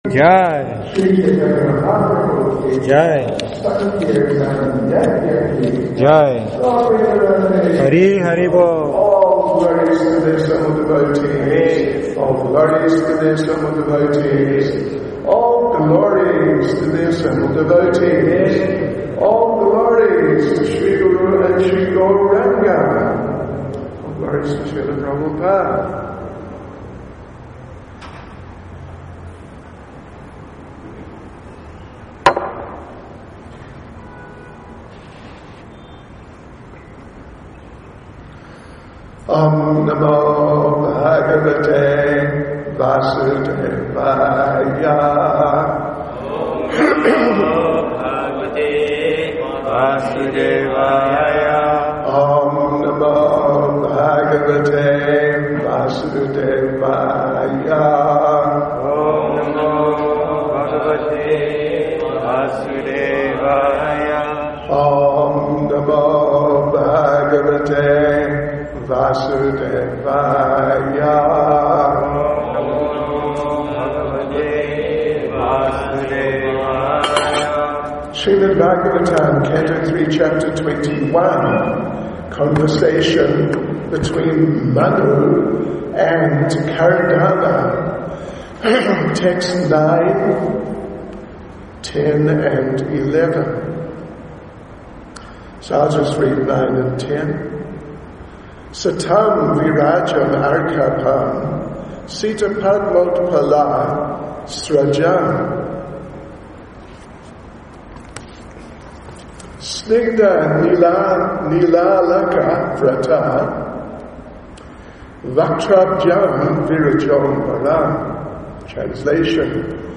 SB 3.21.9-11 Perfection of Yoga is meeting the Supreme Lord face-to-face – ISKCON Durban, South Africa